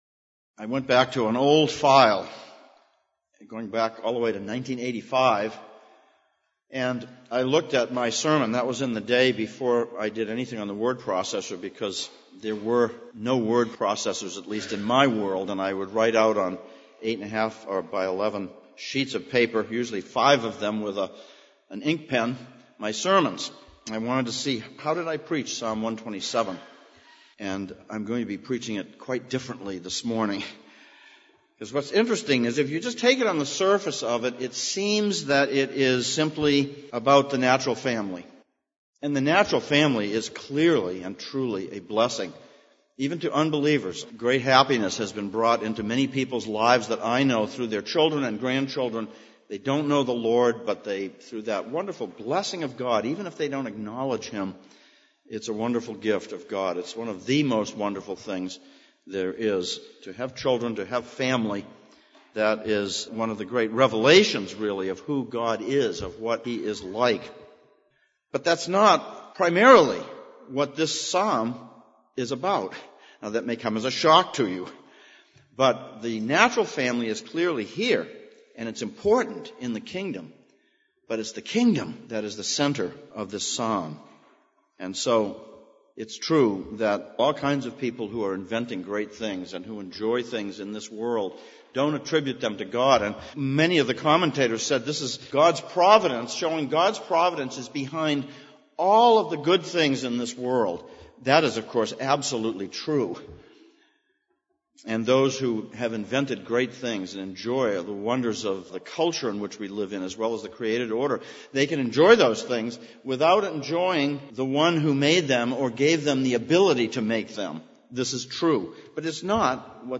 Psalms of Ascents Passage: Psalm 127:1-5, 1 Corinthians 3:1-23 Service Type: Sunday Morning « 6.